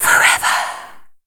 WHISPER 02.wav